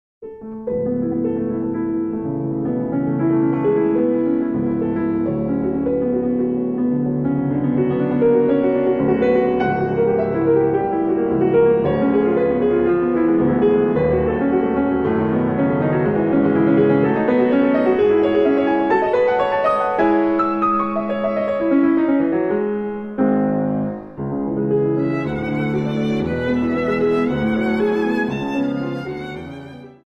piano
violin
Allegro molto